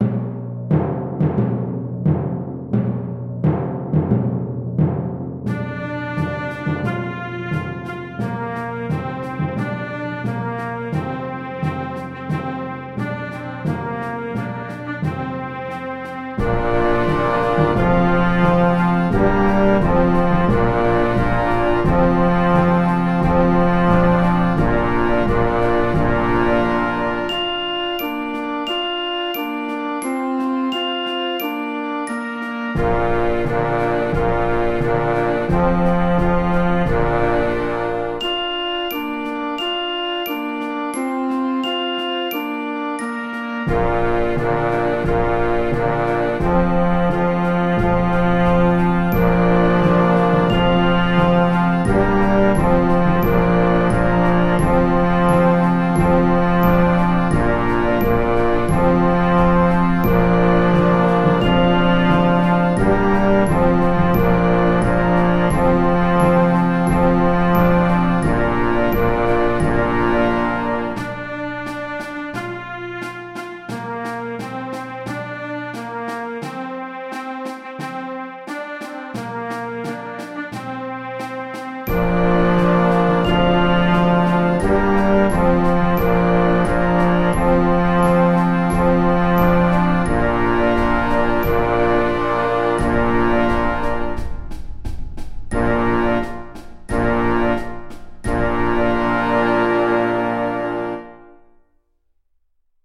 Bugler's Dream (2019) melody.mp3